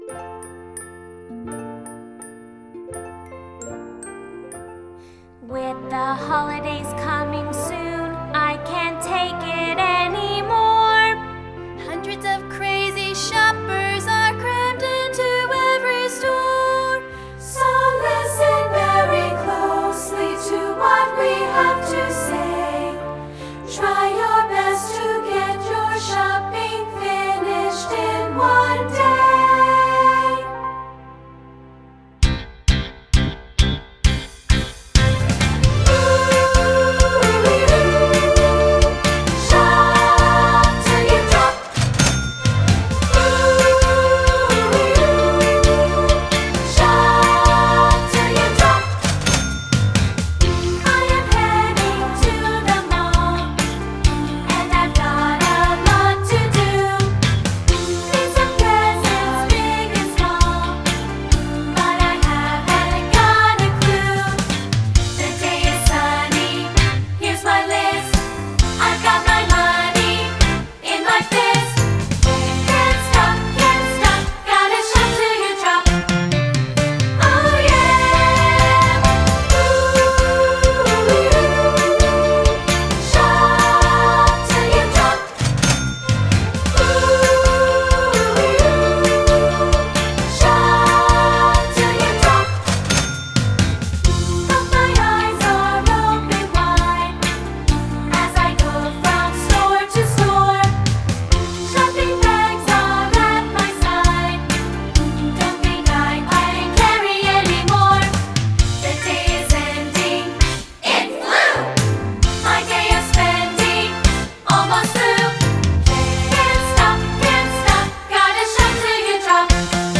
secular choral
- 2-part, sample